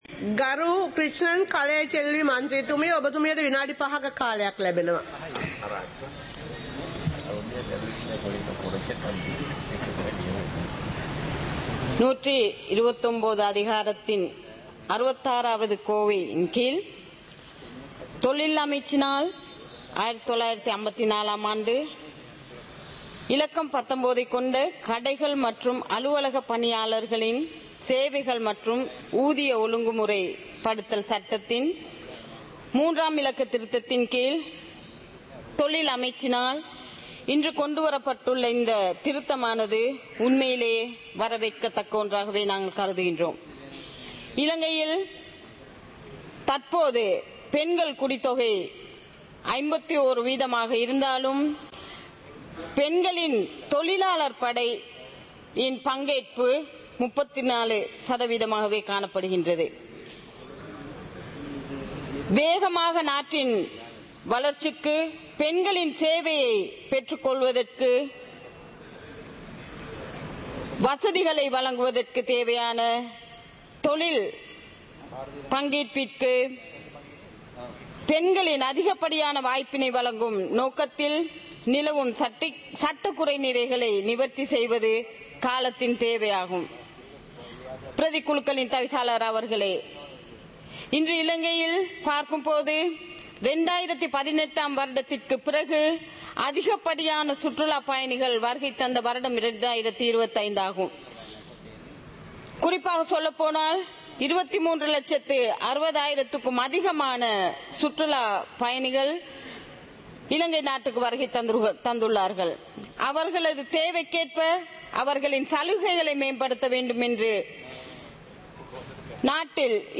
சபை நடவடிக்கைமுறை (2026-01-09)